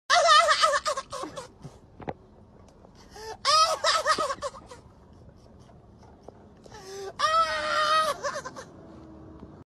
Dog Laughing Meme Sound Effect sound effects free download